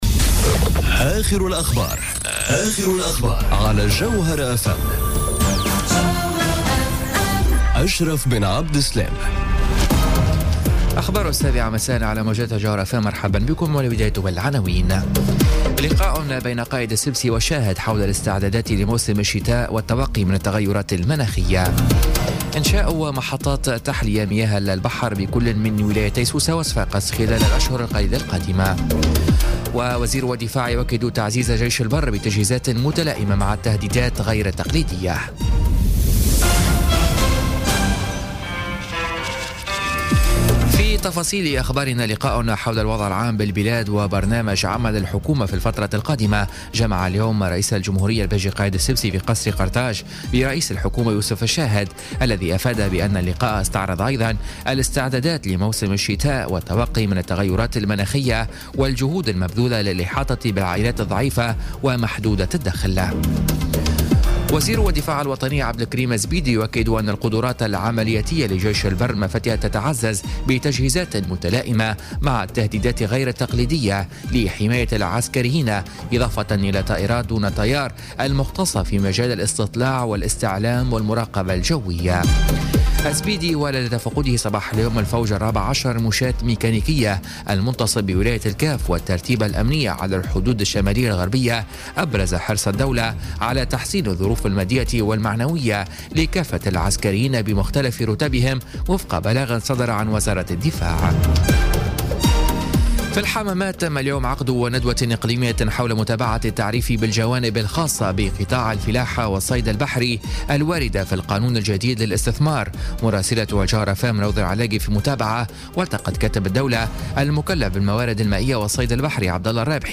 نشرة أخبار السابعة مساء ليوم الخميس 26 أكتوبر 2017